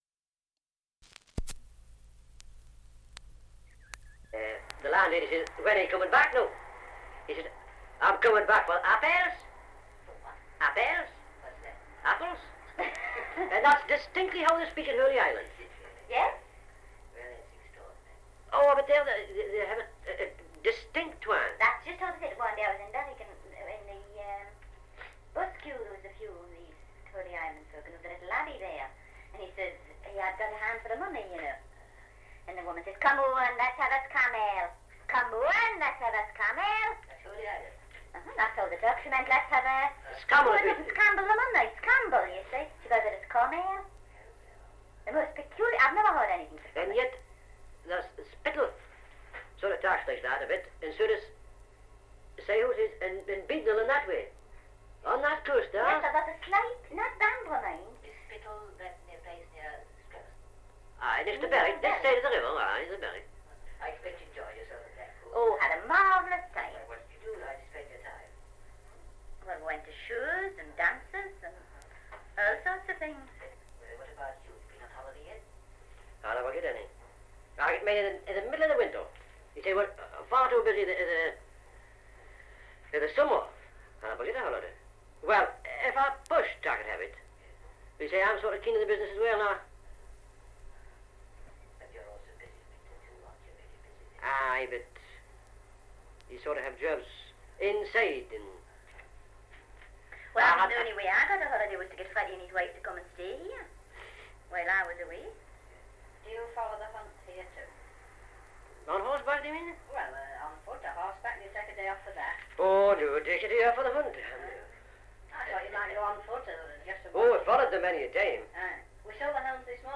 2 - Dialect recording in Belford, Northumberland
78 r.p.m., cellulose nitrate on aluminium
English Language - Dialects